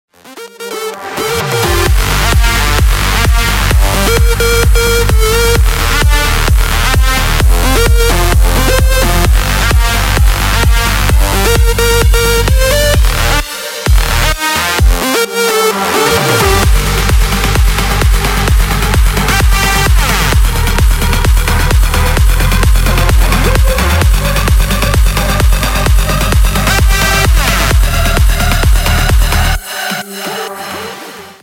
Рингтоны Электроника